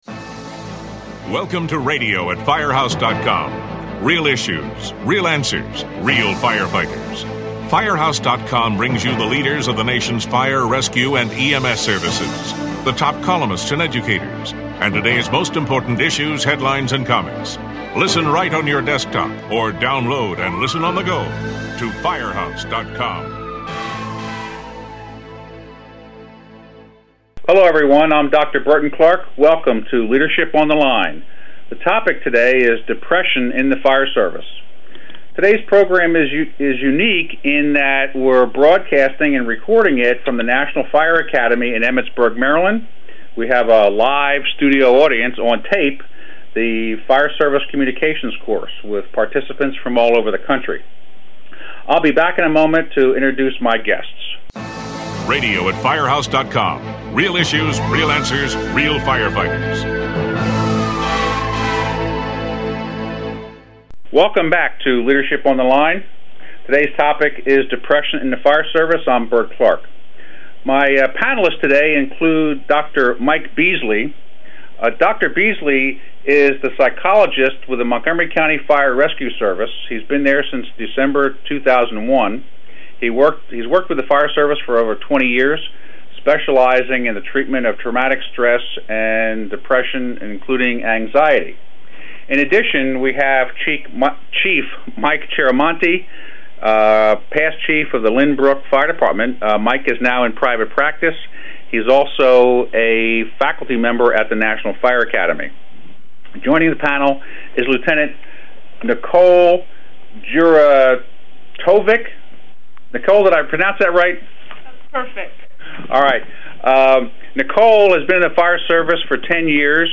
This podcast was recorded with students of the Fire Service Communication Course, held at the National Fire Academy.